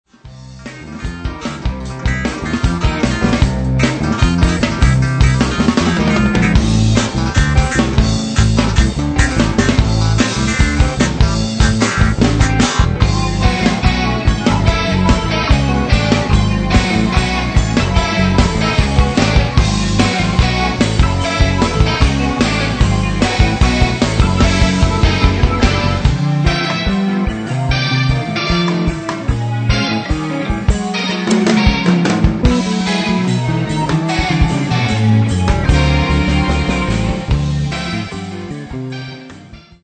Experimental Rock Three Piece
- compositions, vocals, guitar
- drums
- bass, keyboards, guitar
recorded at Midtown Recording Studio